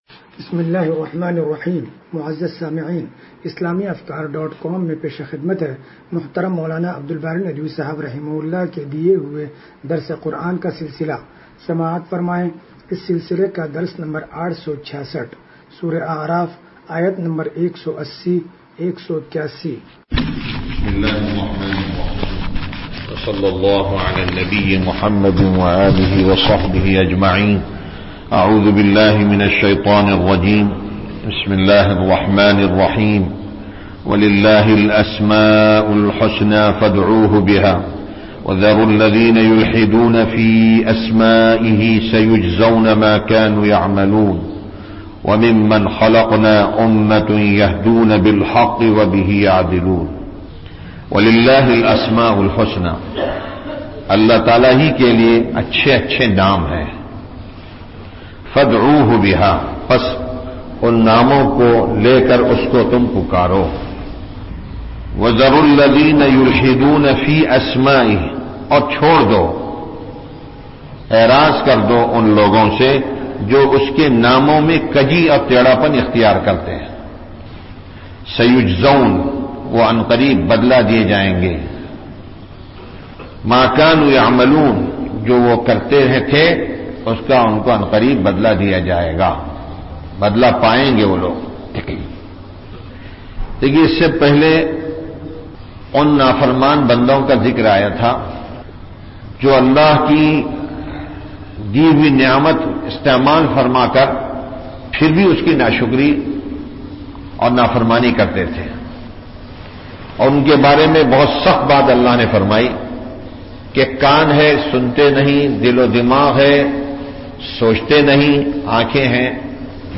درس قرآن نمبر 0866